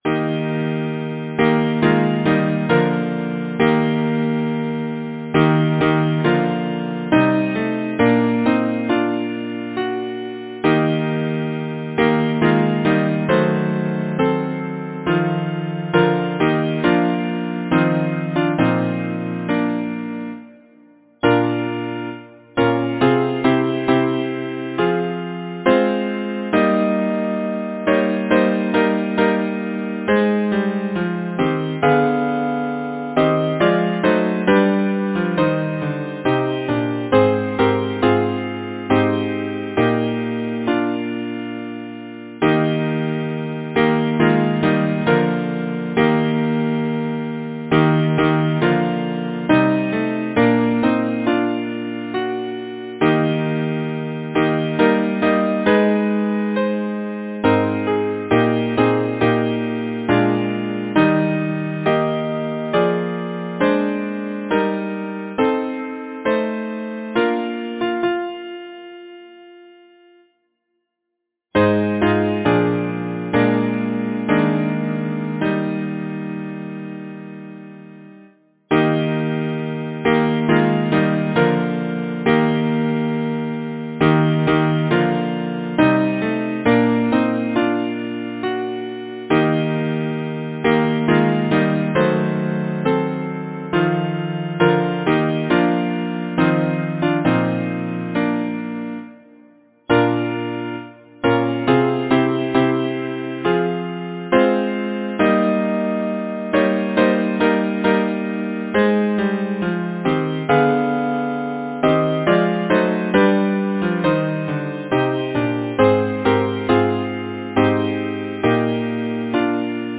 Title: Zephyrs soft their fragrance Composer: John Liptrot Hatton Lyricist: Number of voices: 4vv Voicing: SATB Genre: Secular, Partsong
Language: English Instruments: A cappella